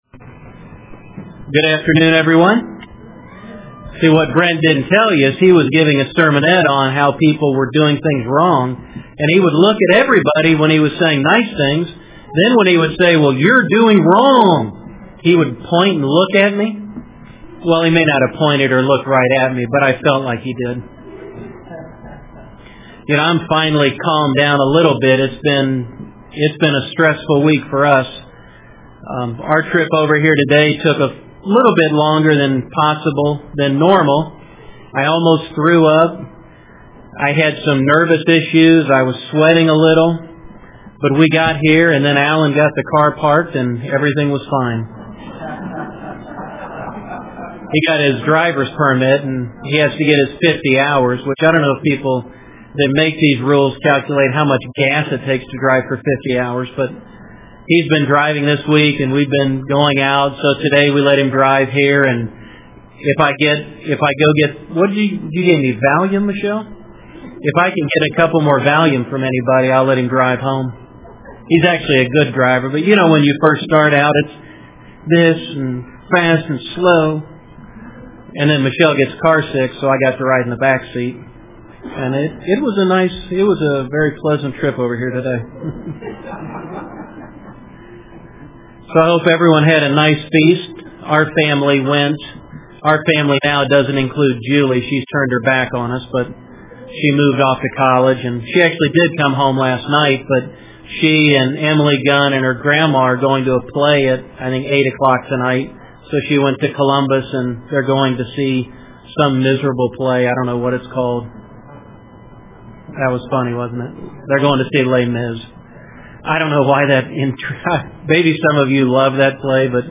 If you knew specifically what God wanted from you, would you feel better about life? This sermon takes us through a parable in the Bible that shows how God works with His people and specifically how God interacts and what He expects from us.